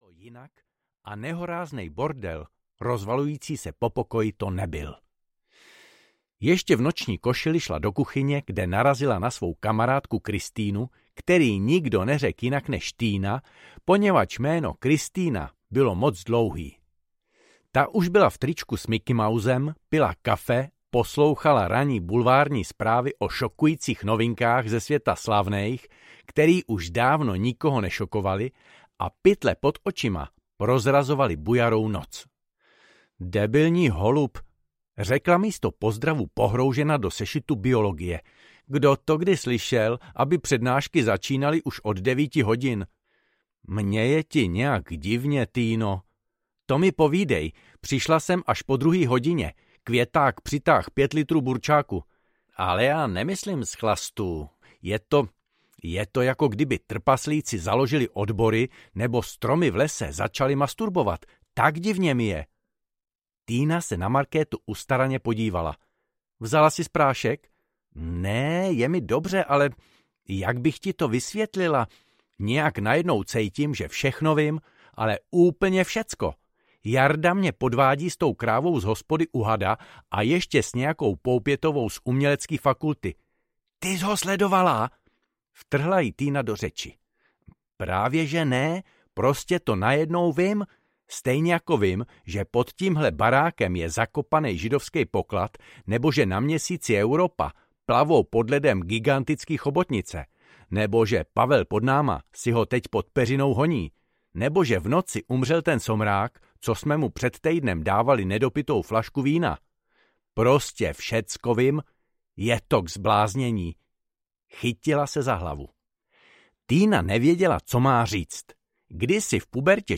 Kejda audiokniha
Ukázka z knihy